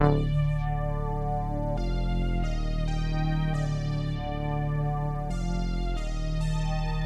标签： 136 bpm Trap Loops Bass Synth Loops 1.19 MB wav Key : D
声道立体声